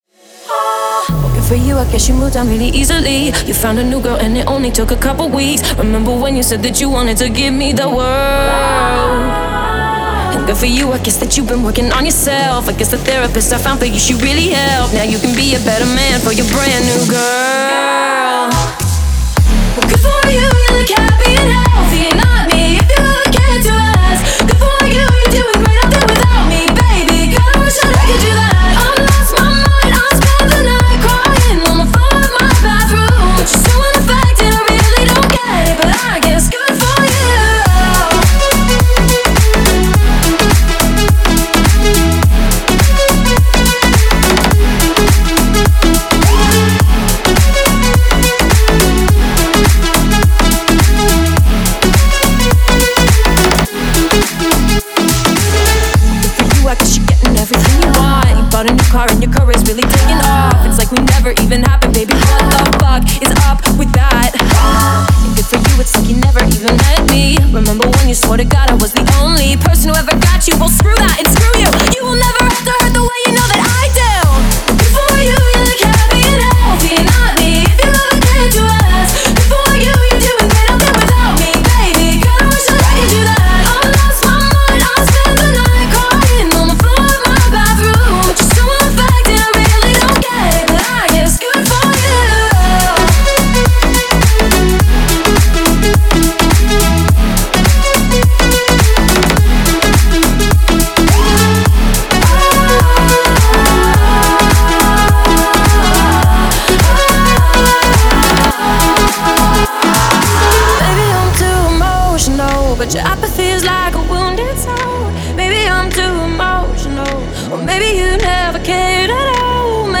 а динамичные биты заставляют двигаться в такт.